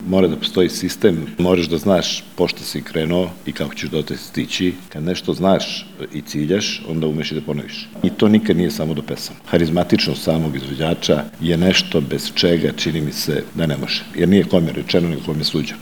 Jedan od panela današnjeg susreta bila je i diskusija s temom "Napuniti Arenu" na kojoj su stručnjaci razgovarali o razlozima zbog kojih izvođači pune poznatu zagrebačku koncertnu dvoranu.